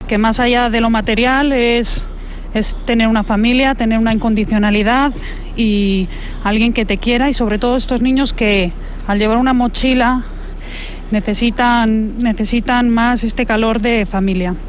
Audio: Una madre de acogida señala que más allá de lo material lo que necesitan es alguien que les quiera, un calor de familia.